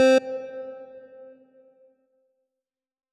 C1.wav